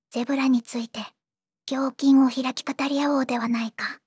voicevox-voice-corpus
voicevox-voice-corpus / ROHAN-corpus /ずんだもん_ヒソヒソ /ROHAN4600_0040.wav